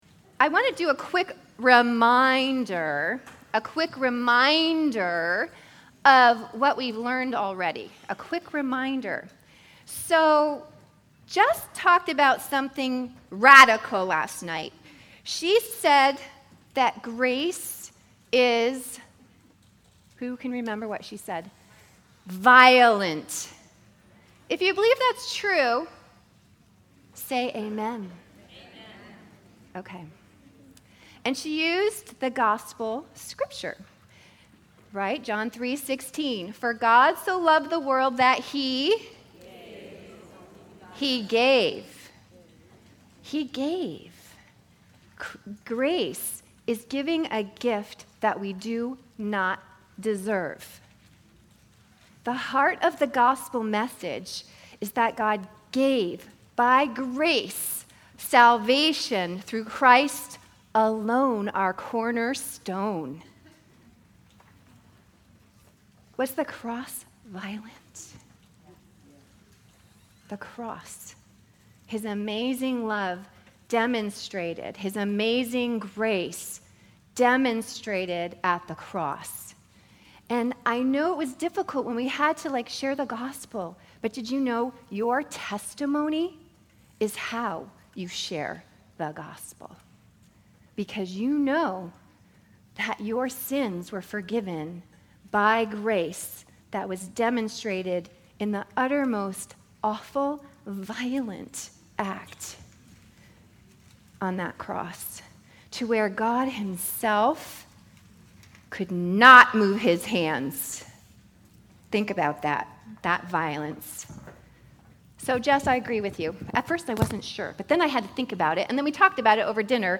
Women's Retreat 2014